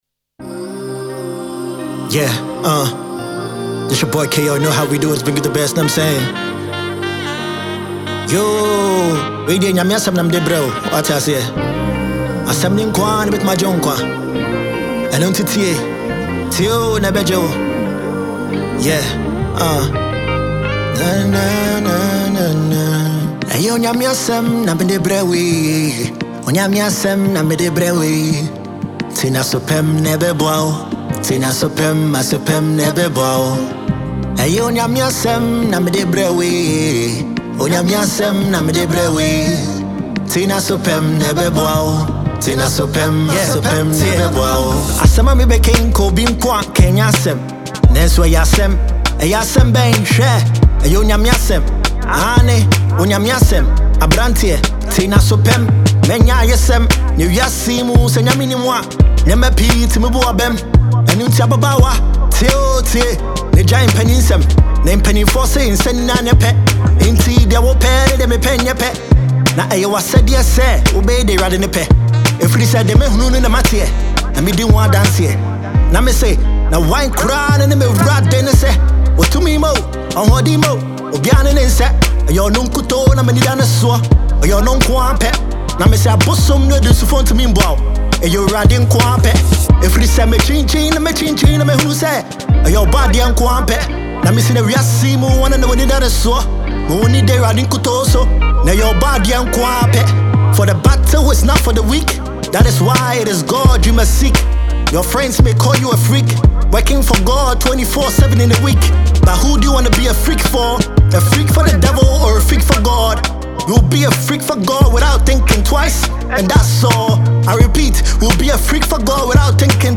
soul-stirring
Ghana Gospel Music